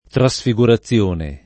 [ tra S fi g ura ZZL1 ne ]